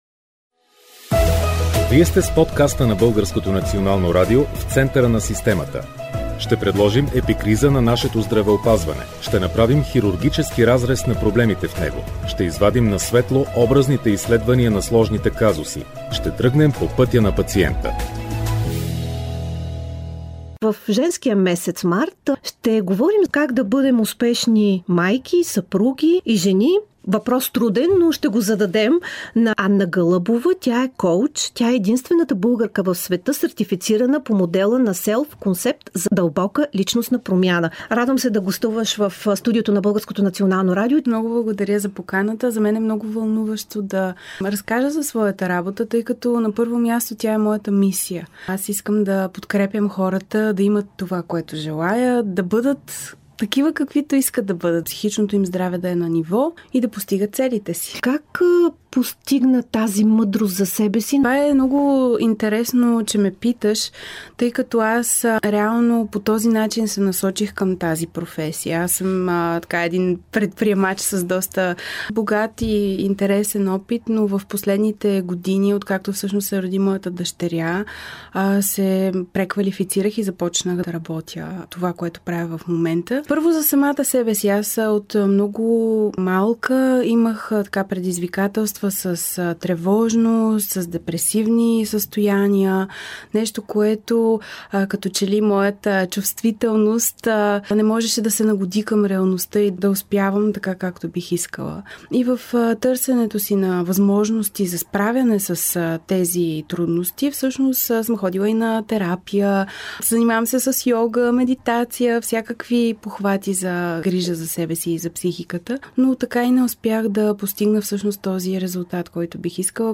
Експеримент на живо с водещата в този епизод за излизане от „зоната на комфорт“.